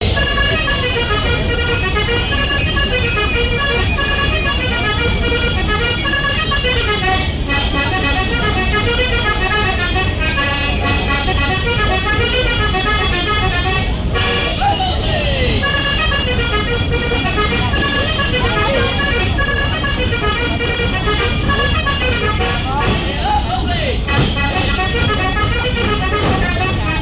danse : branle : avant-deux
lors d'une kermesse
Pièce musicale inédite